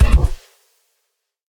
Minecraft Version Minecraft Version snapshot Latest Release | Latest Snapshot snapshot / assets / minecraft / sounds / mob / camel / dash1.ogg Compare With Compare With Latest Release | Latest Snapshot